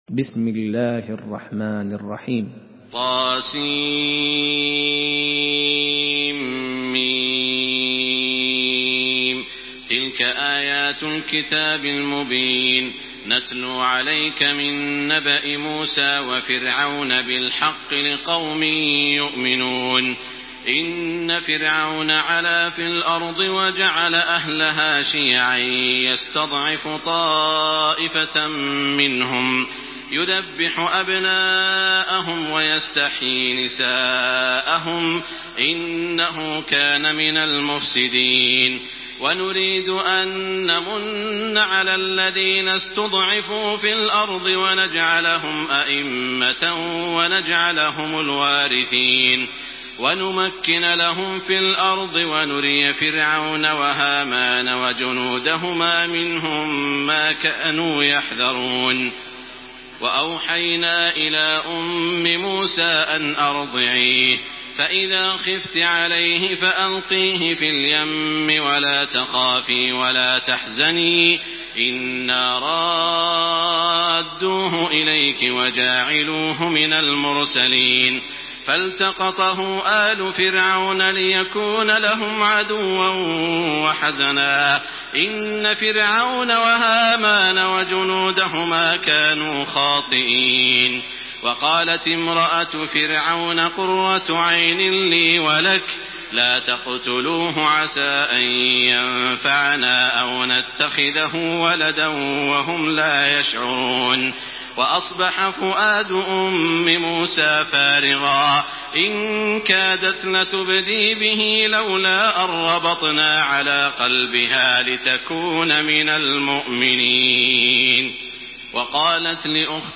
Surah Al-Qasas MP3 in the Voice of Makkah Taraweeh 1428 in Hafs Narration
Murattal